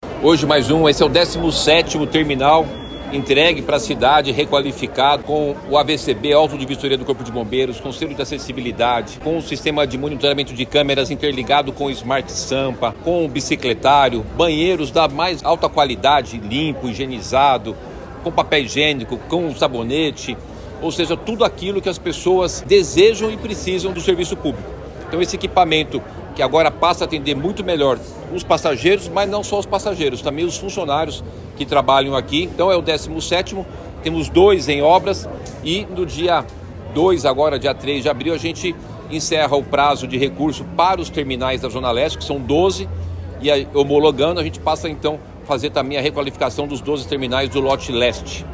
Declaração foi feita durante entrega de terminal reformado na zona Oeste
A promessa é do prefeito Ricardo Nunes nesta sexta-feira, 28 de março de 2025, durante entrega de obras de reforma do terminal Lapa, na zona Oeste, que já foi concedido e faz parte de outro lote de terminais.